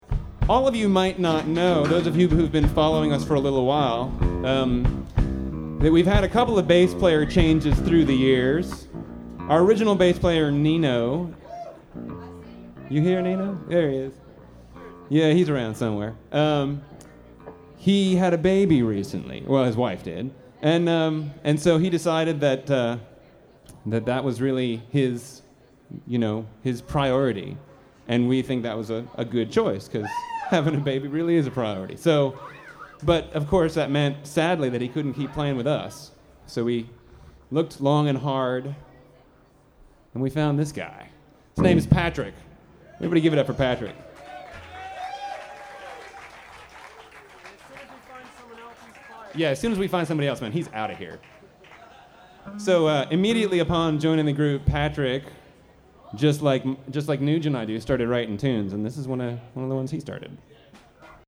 Last Concert Café, 10/23/04
Talking
Source 2: Nomad Jukebox III recording (w/ On-Stage Sony Stereo Mic) > WAV